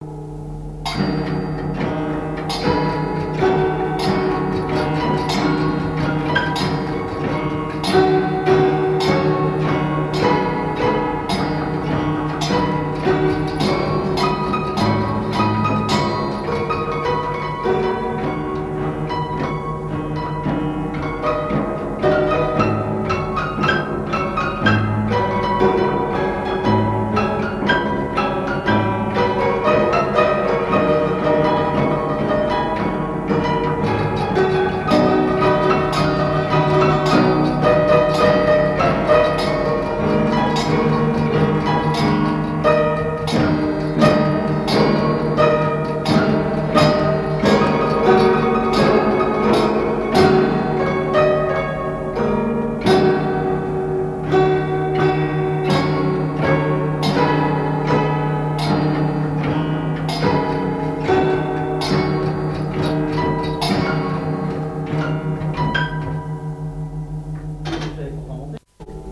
Air n° 8 du piano mécanique
piano mécanique
Pièce musicale inédite